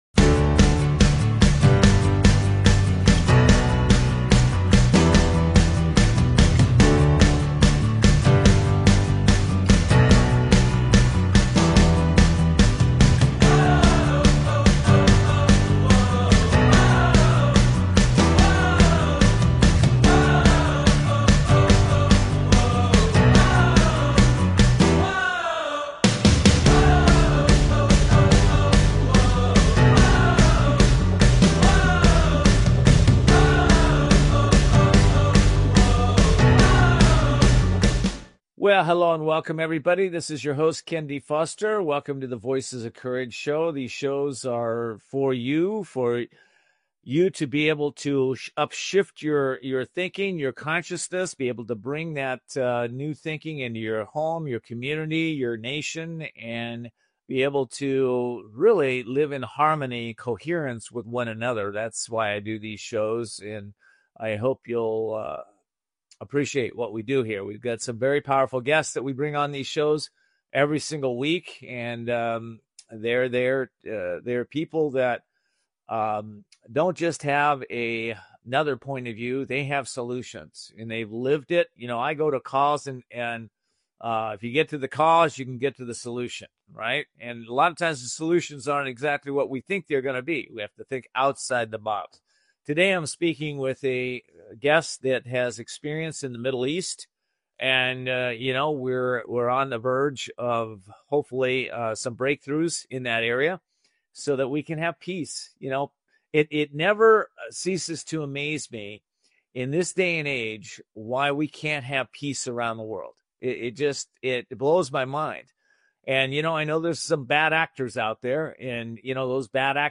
Voices of Courage Talk Show